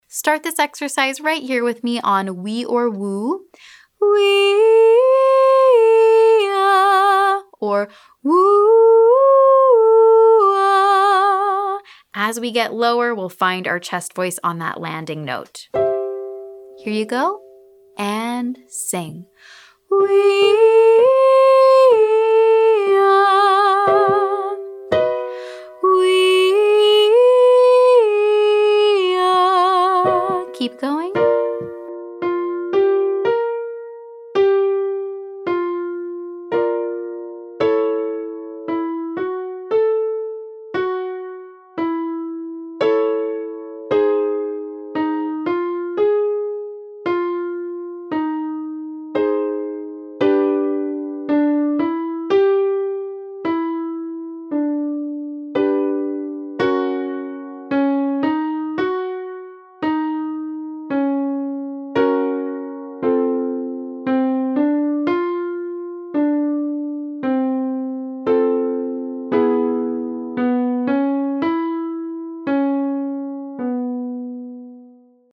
Exercise 2:  WEE/OO to UH 568–6 5 head voice down
The following exercise helps us go in the opposite direction: from head voice to more of a chest dominant sound.